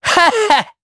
Neraxis-Vox-Laugh_jp.wav